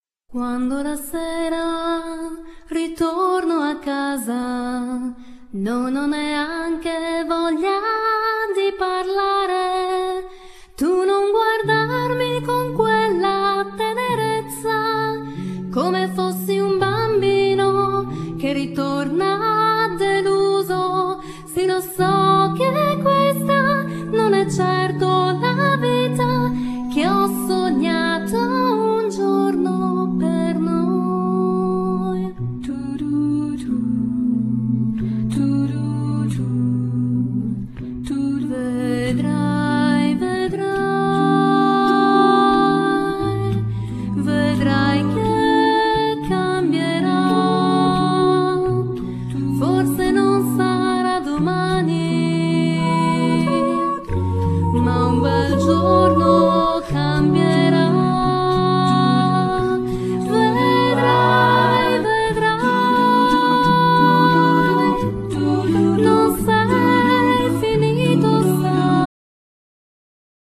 Genere : Pop / Jazz a cappella